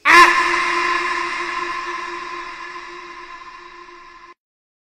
Efek suara Akh
Kategori: Suara viral
Keterangan: Efek suara Akh meme cocok untuk video lucu dan komedi.
efek-suara-akh-id-www_tiengdong_com.mp3